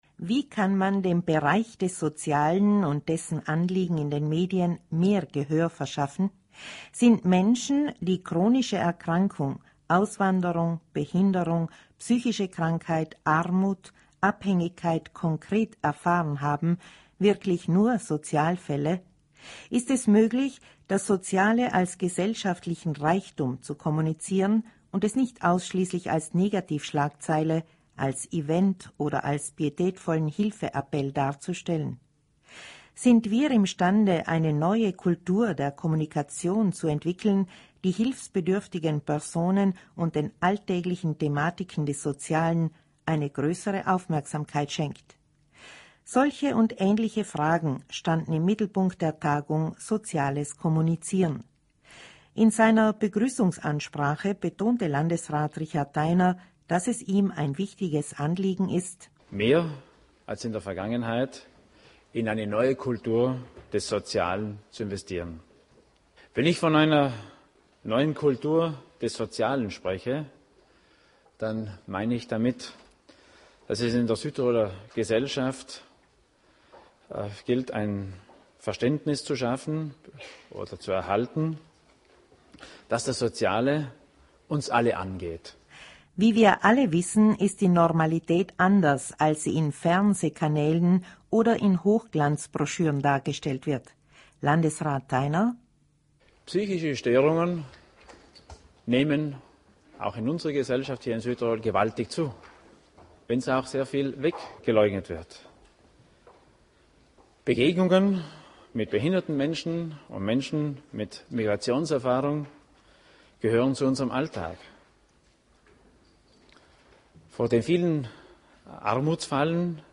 soz_kom_radiobericht.mp3